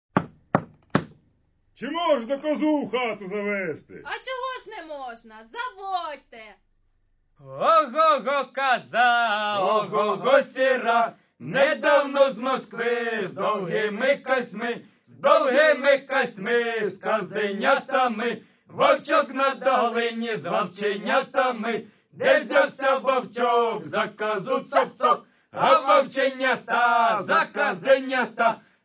Каталог -> Народная -> Аутентичное исполнение